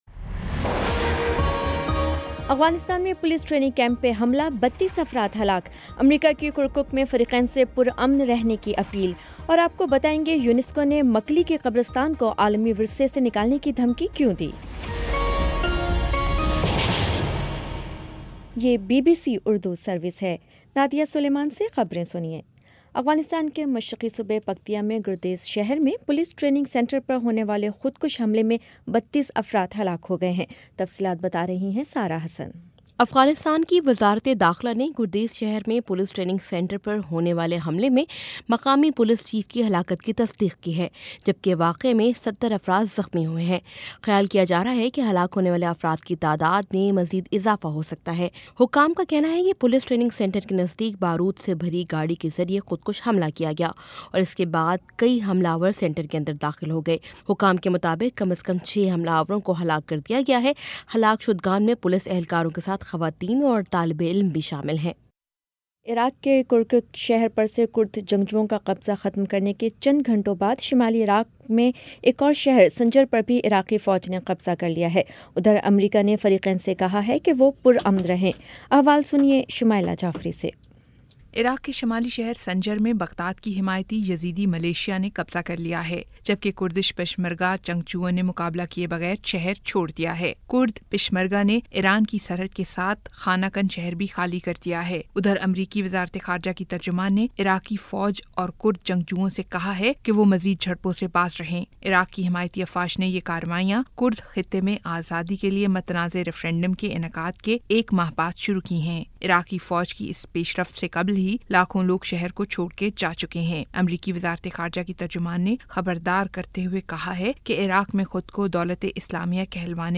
اکتوبر 17 : شام پانچ بجے کا نیوز بُلیٹن